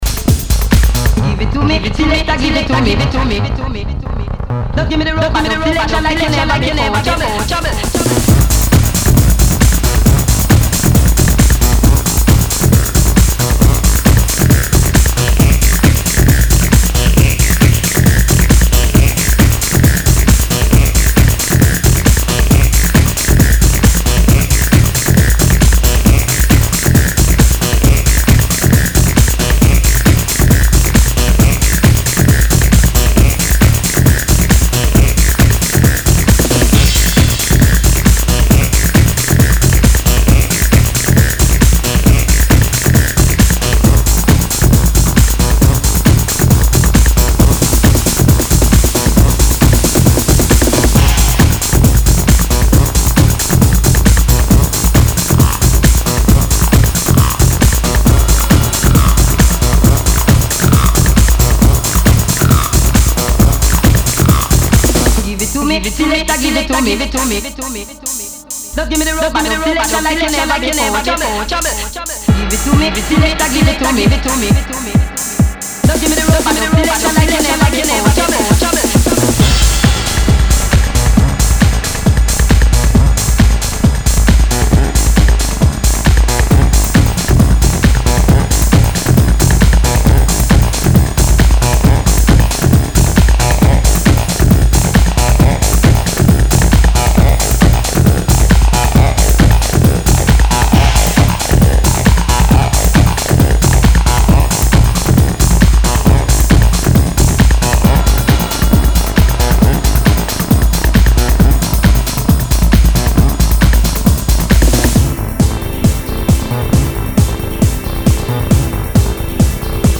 Acid House